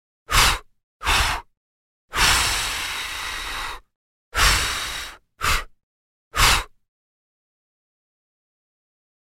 Человек дует воздухом